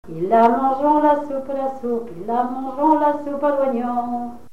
Chants brefs - De noces
Genre brève
Témoignes de vie
Pièce musicale inédite